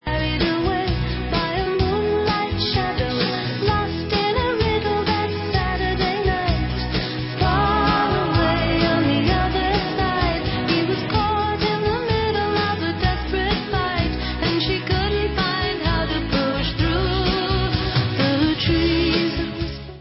Original album remastered